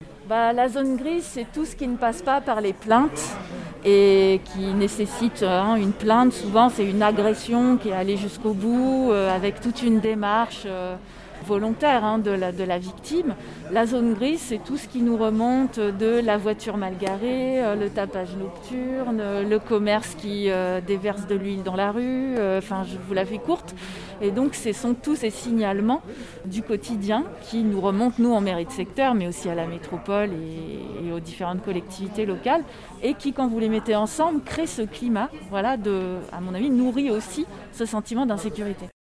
son_copie_petit-435.jpgLa maire des 1/7 définit ce qu’est une “zone grise”
sophie_camard_debut_partie_2_la_zone_grise.mp3